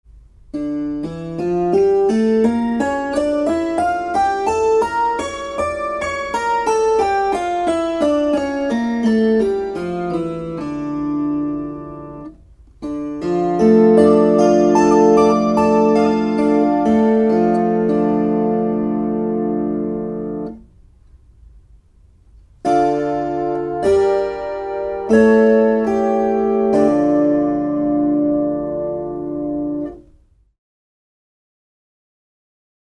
Kuuntele d-molli. b cis Opettele duurit C G D A E F B Es As mollit a e h fis cis g c f Tästä pääset harjoittelun etusivulle.
dmolli.mp3